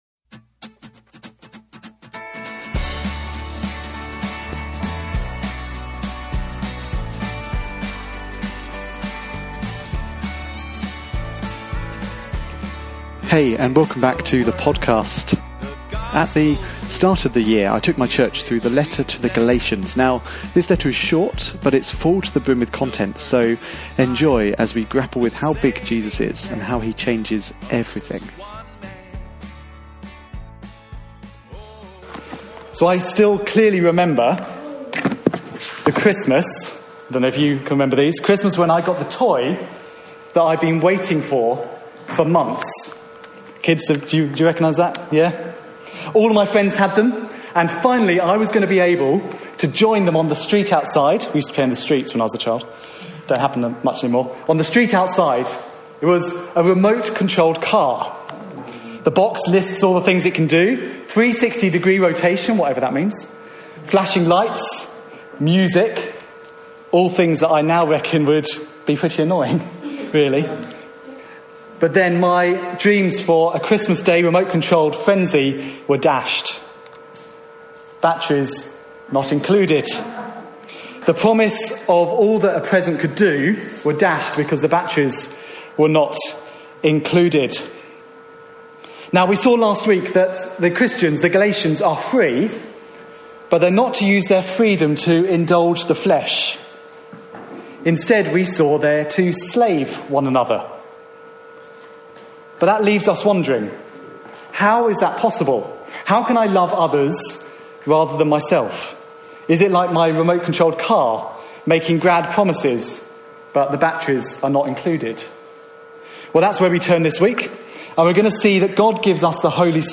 This evening we looked at Galatians 5:16-24, and saw that we are called to live by the Spirit, not by the flesh. Preached at Christ Church Hemel on 31st March 2019